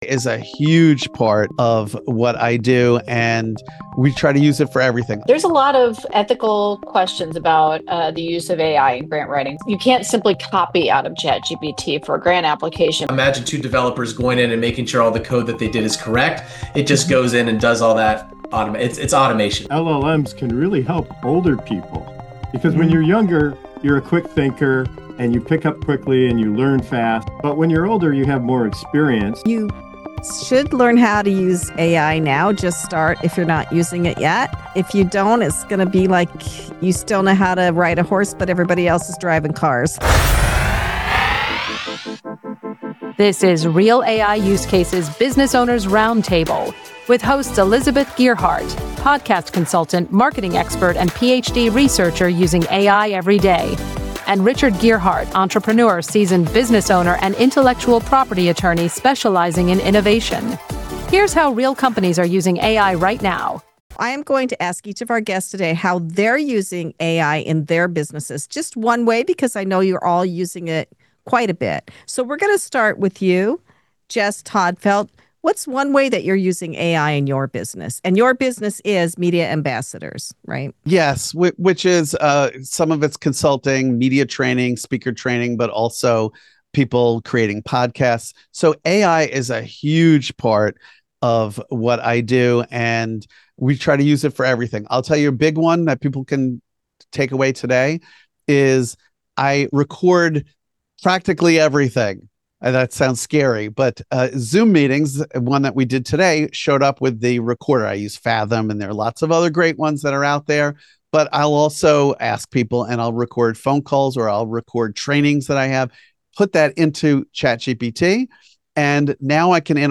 Real AI Use Cases Business Owners Roundtable Is AI a shortcut or a super-assistant?